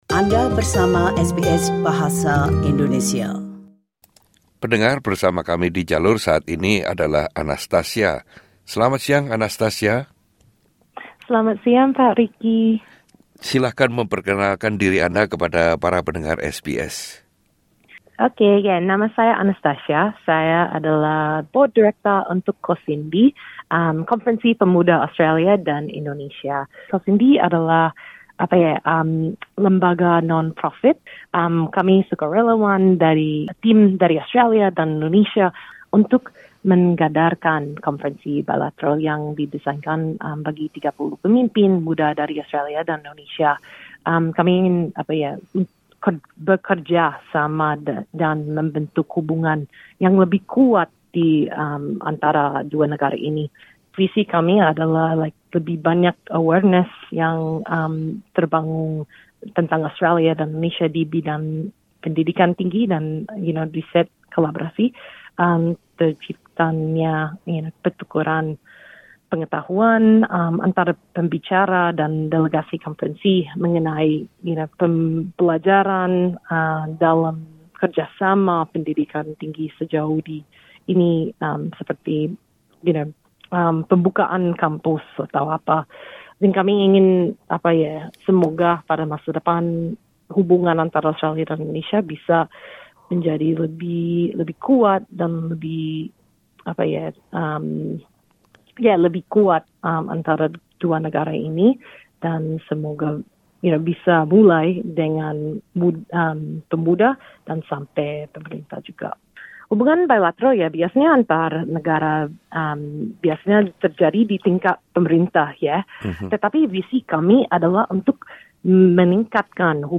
SBS Indonesian berbincang dengan